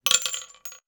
weapon_ammo_drop_24.wav